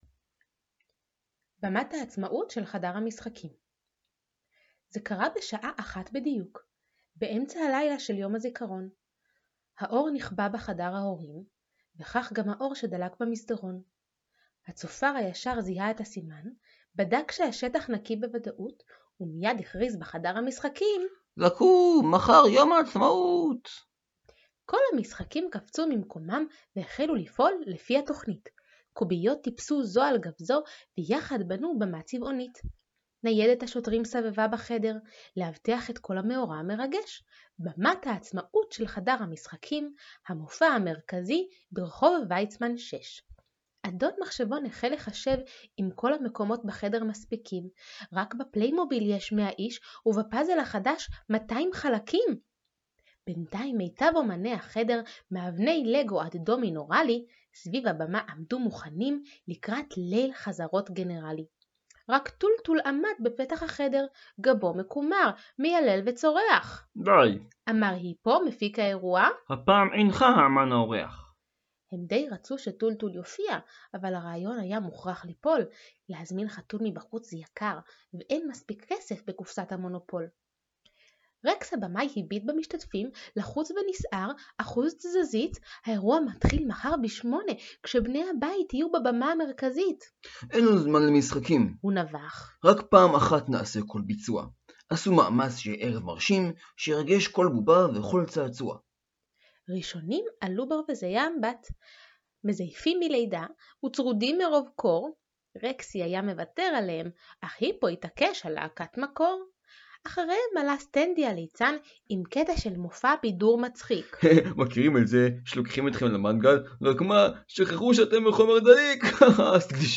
12-סיפור-לפני-השינה-במת-העצמאות-של-חדר-המשחקים.mp3